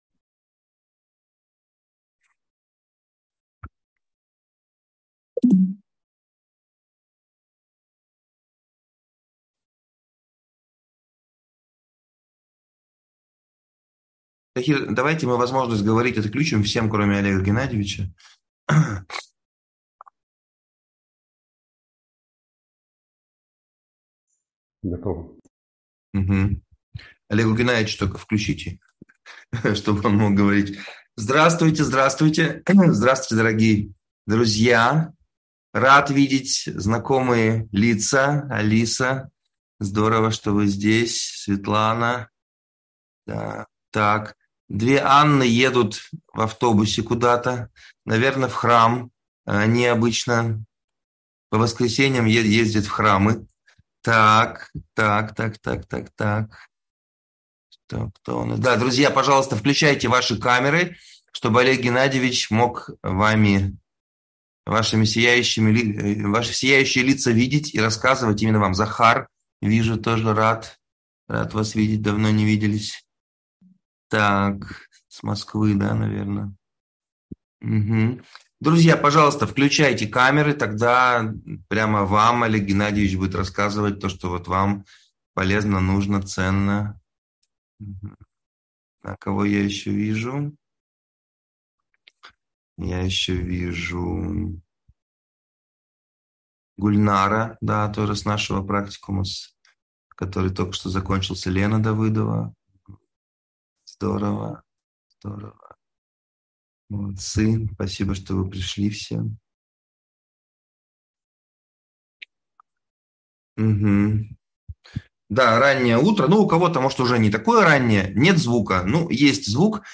Твоя миссия жизни (вебинар, 2023)